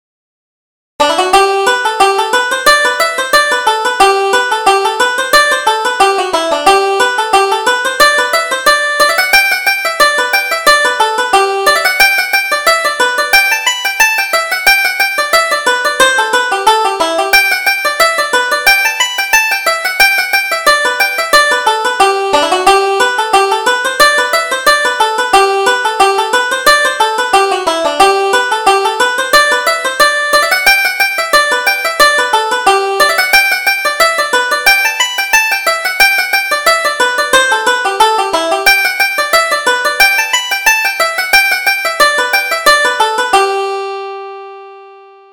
Reel: Paddy's Surprise